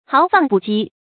豪放不羈 注音： ㄏㄠˊ ㄈㄤˋ ㄅㄨˋ ㄐㄧ 讀音讀法： 意思解釋： 羈：束縛。形容人性情豪邁直爽氣魄大而不受拘束。